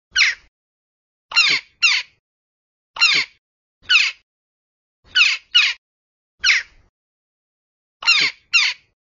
На этой странице собраны звуки галки – от характерных криков до пересвистов с сородичами.
Послушайте звуки галки